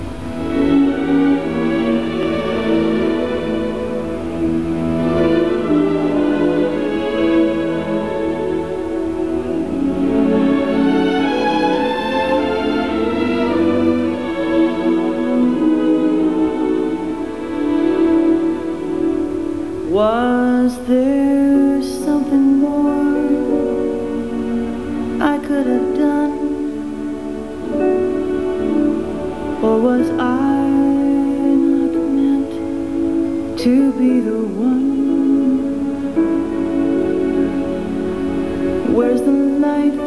28k 56k 100k _____________ Thème musical